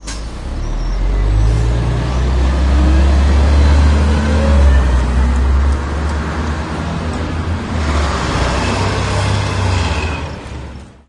B U S E S
描述：出发的公交车在乌特勒支中央火车站，在公交车终点站记录。
Tag: 总线 出发日期 驱动器